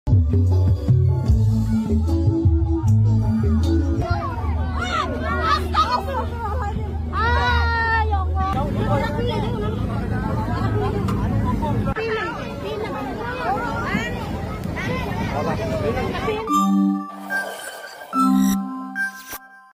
Detik-detik mencekam saat seorang perempuan terjatuh dan terlindas truk sound horeg dalam sebuah iring-iringan. Suasana yang awalnya penuh musik dan sorak sorai berubah jadi kepanikan.